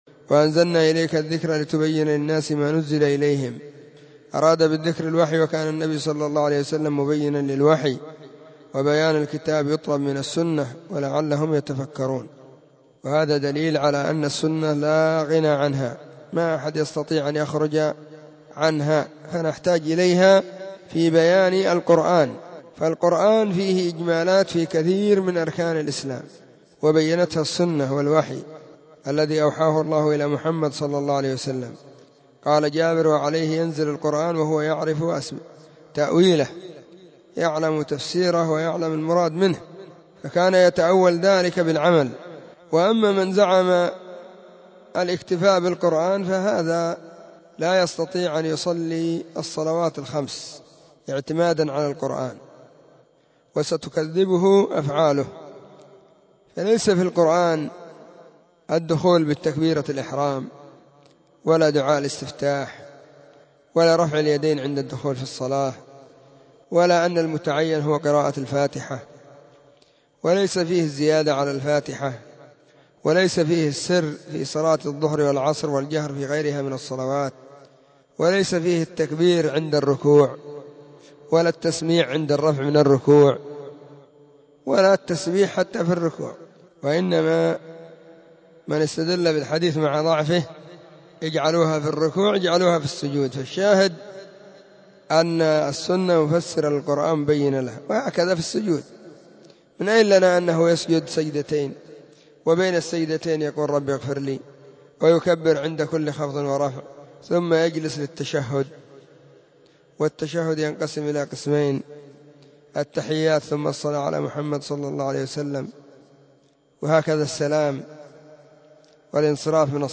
📢 مسجد الصحابة – بالغيضة – المهرة – اليمن حرسها الله.
الثلاثاء 21 صفر 1443 هــــ | الردود الصوتية | شارك بتعليقك